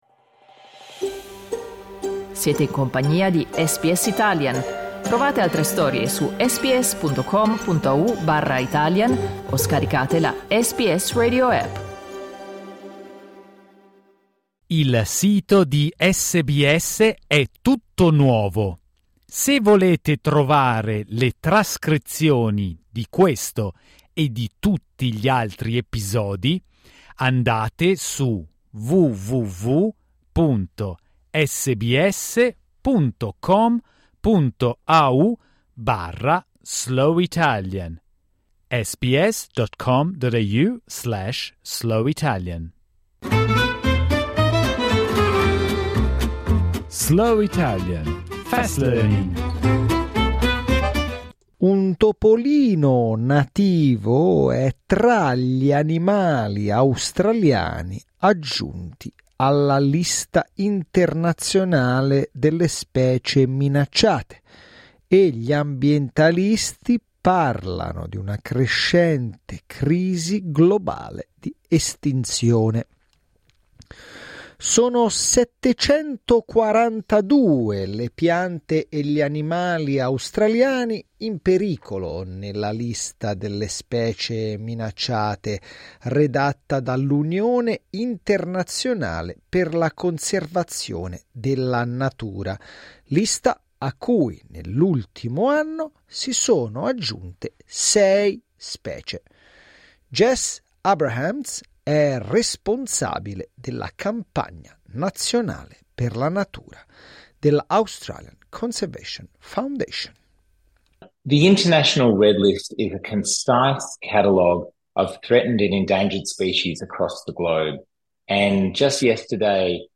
Slow Italian, Fast Learning